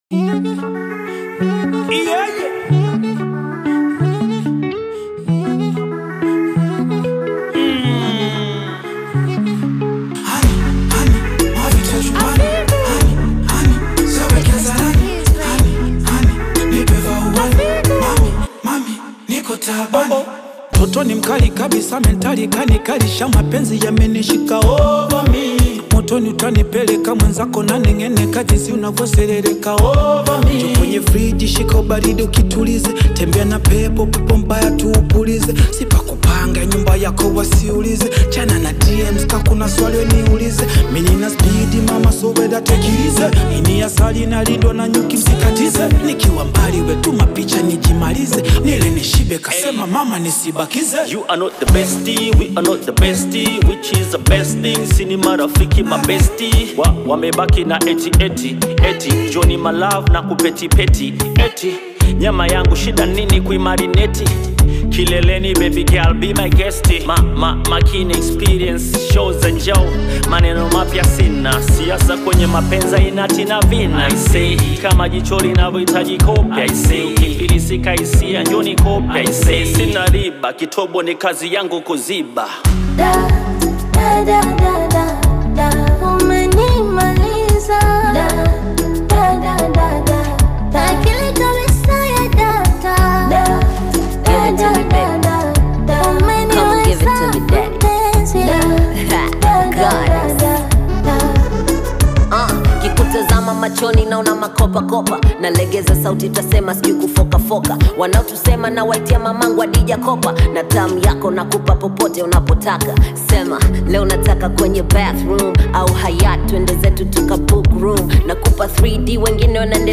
this is a hip hop version.
a Hip Hop remix